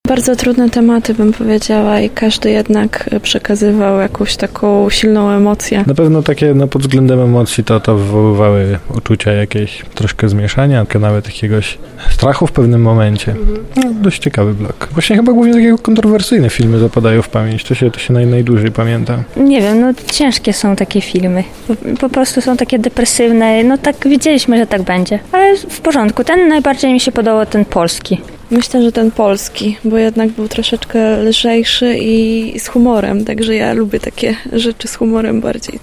O tym jak minęły pierwsze dni Festiwalu mówią uczestnicy